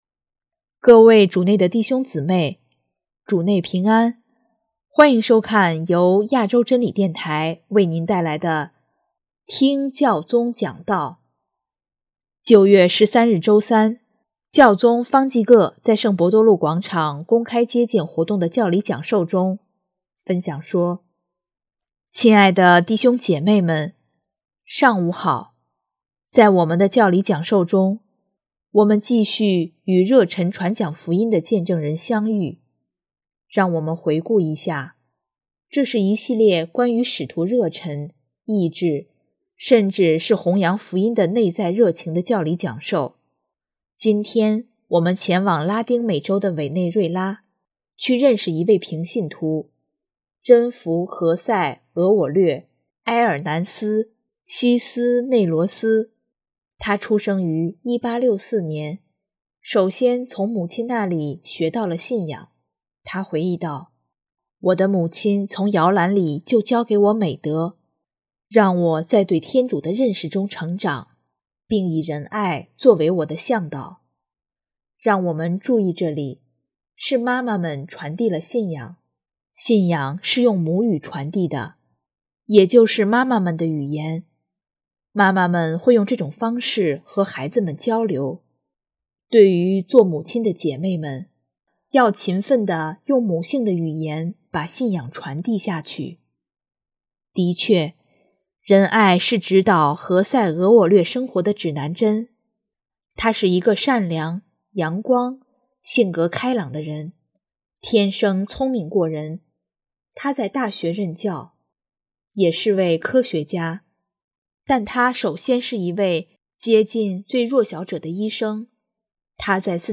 9月13日周三，教宗方济各在圣伯多禄广场公开接见活动的教理讲授中，分享说：